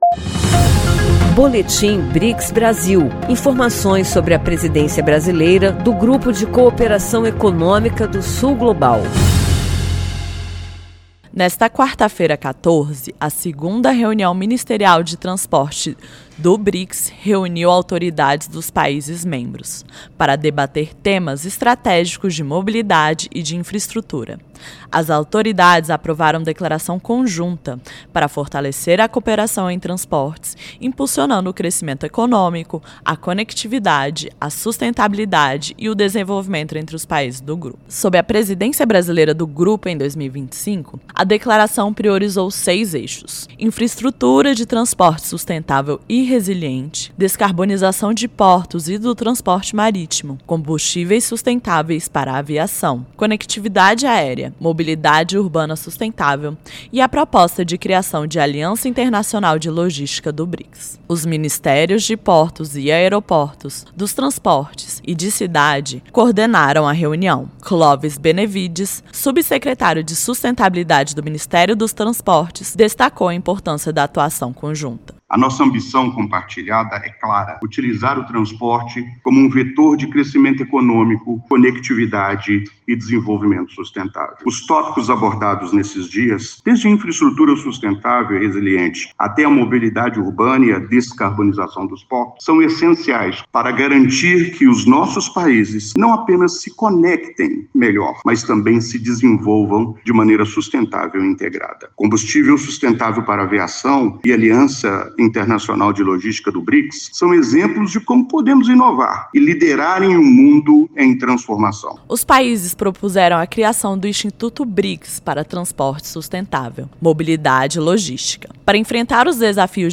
Em agendas na Rússia e China, o presidente Luiz Inácio Lula da Silva destacou o BRICS como motor do Sul Global, defendeu ordem multipolar, firmou parcerias estratégicas e promoveu o multilateralismo e o desenvolvimento sustentável. Ouça a reportagem e saiba mais.
boletim-ministerial.mp3